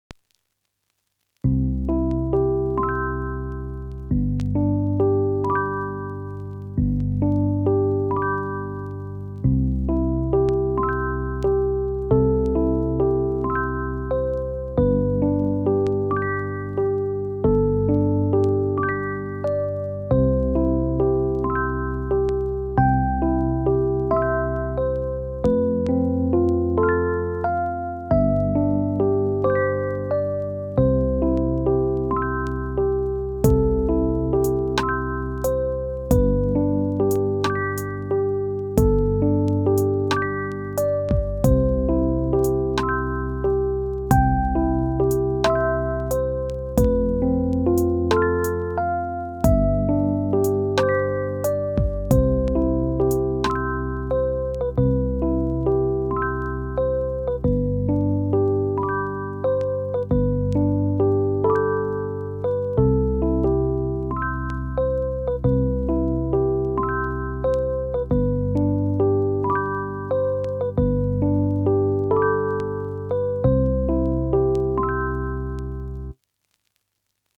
Making something we heard a million times sounds different was small challenge to me, so I had a little fun with this one. I also mixed it as lofi music to make it sounds warmer and more relaxing.